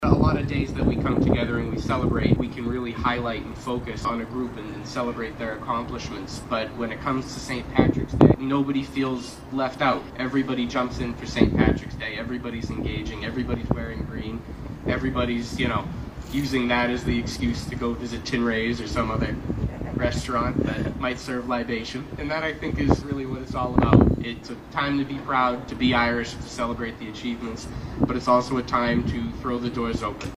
City Council President John Lally said the holiday is an Irish celebration where all are welcomed to take part.